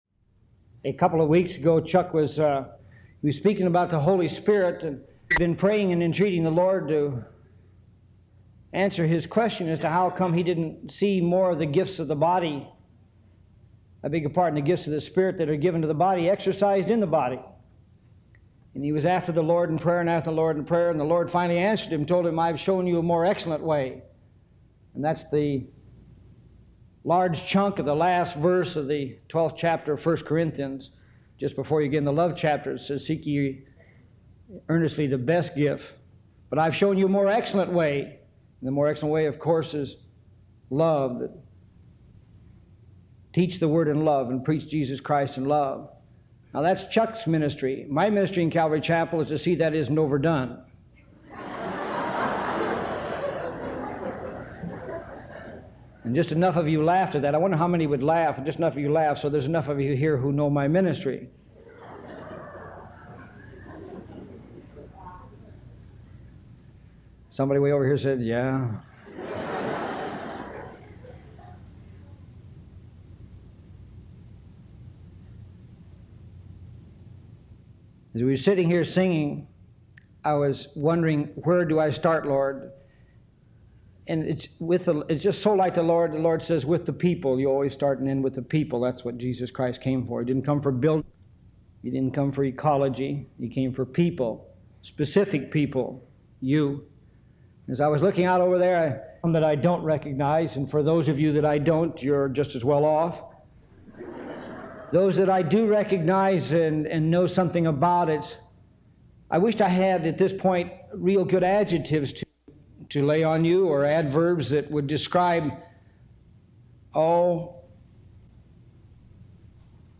Ministering to the Body download sermon mp3 download sermon notes Welcome to Calvary Chapel Knoxville!